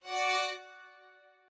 strings4_41.ogg